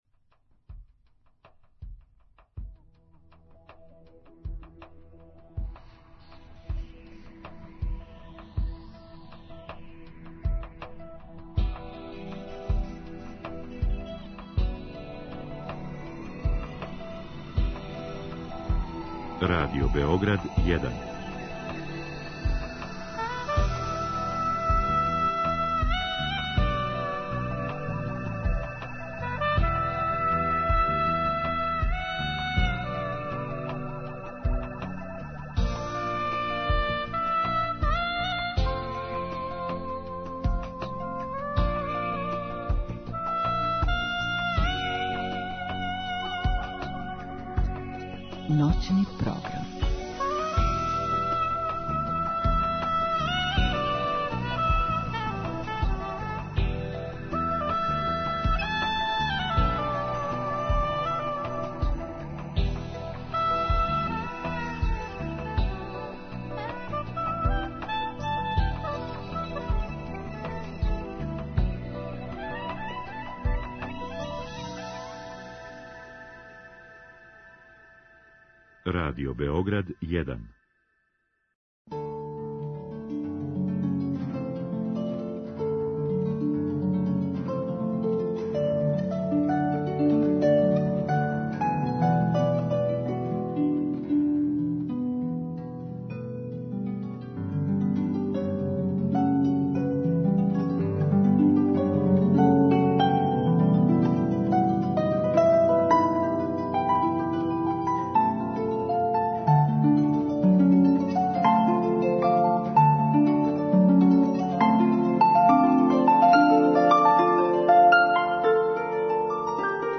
U našoj emisiji " Iza violinskog ključa" slušaćete različita dela koja su izvođena tokom sezone. Takođe ćete čuti koje novitete su prezentovali članovi Narodnog pozorišta za vreme epidemiološke situacije kao i poteškoće sa kojima su se susreli.